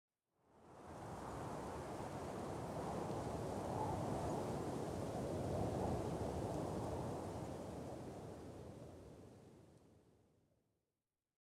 wind1.ogg